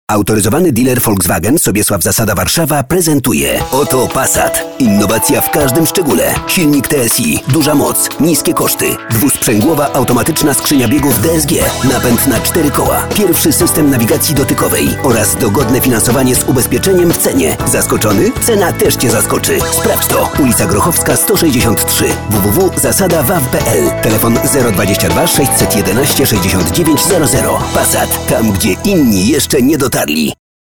Sprecher polnisch für TV / Rundfunk / Industrie.
Sprechprobe: eLearning (Muttersprache):
Professionell voice over artist from Poland.